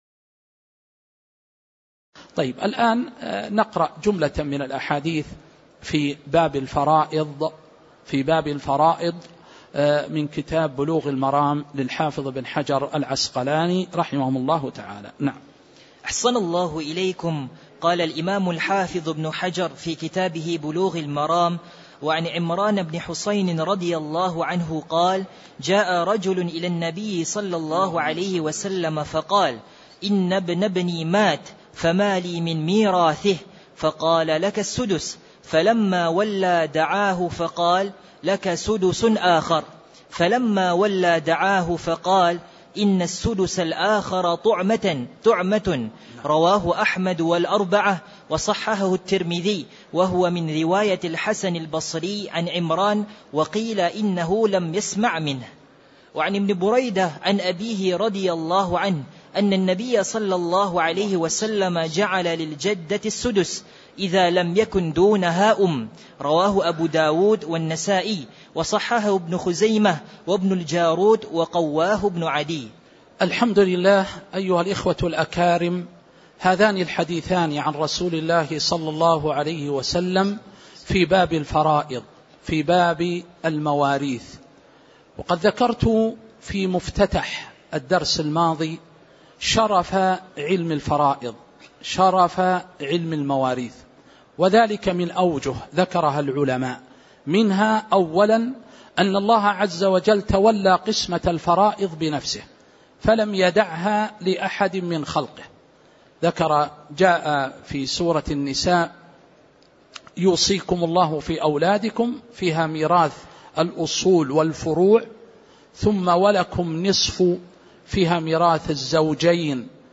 تاريخ النشر ٢٥ رجب ١٤٤٦ هـ المكان: المسجد النبوي الشيخ